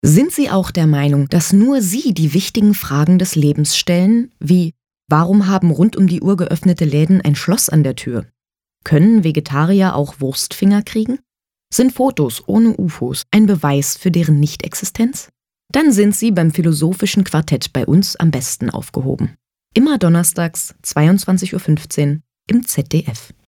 Sprechprobe: Trailer